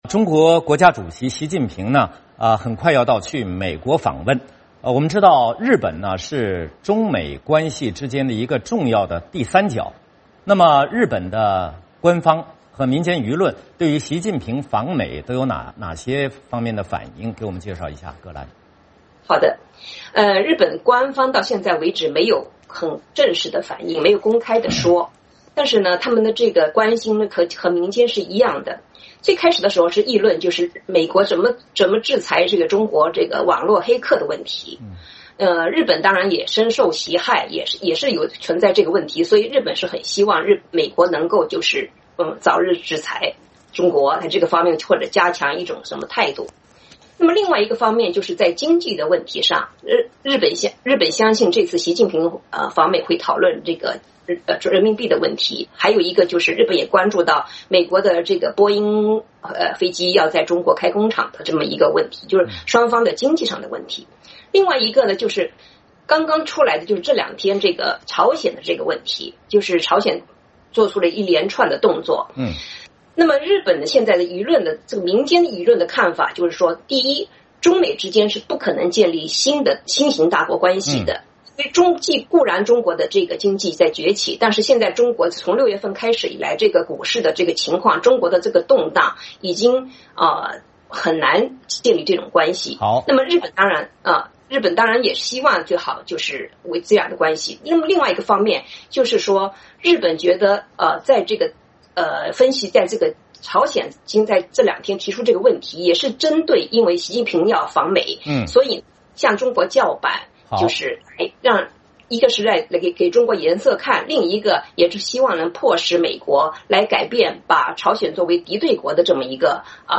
VOA连线：日本关注习近平访美